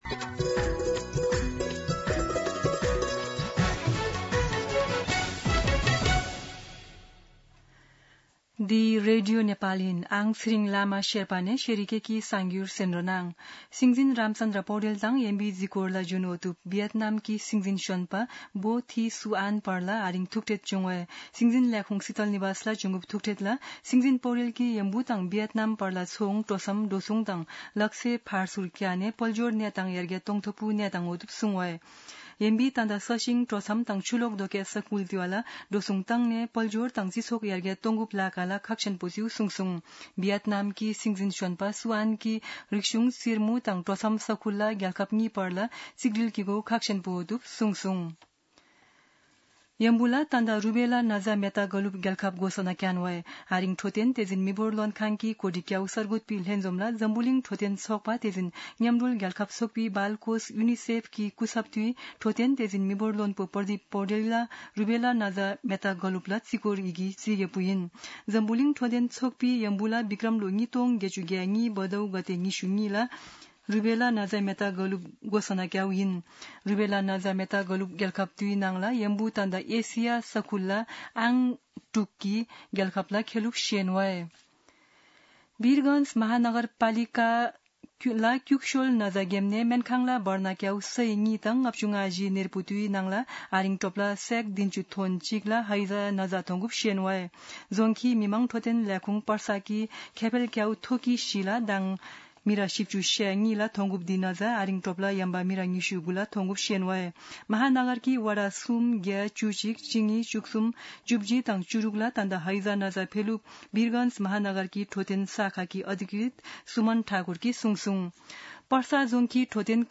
शेर्पा भाषाको समाचार : ९ भदौ , २०८२
Sherpa-News-5-9.mp3